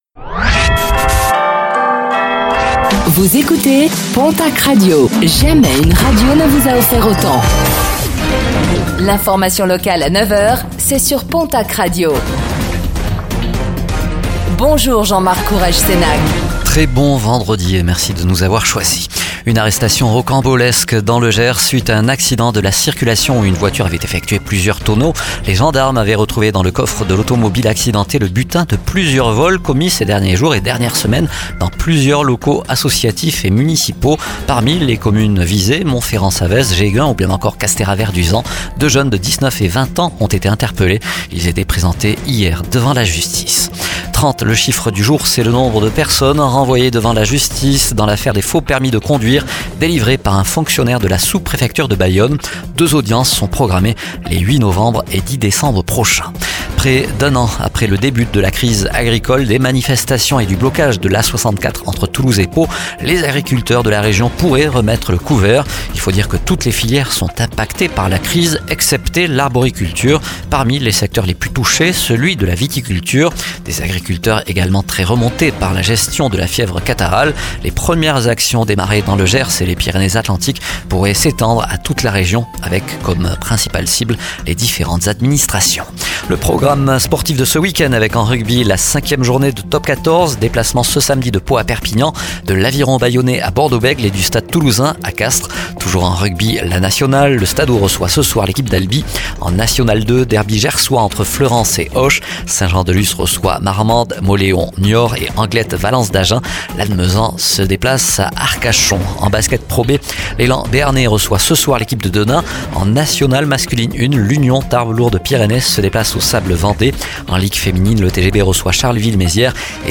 09:05 Écouter le podcast Télécharger le podcast Réécoutez le flash d'information locale de ce vendredi 04 octobre 2024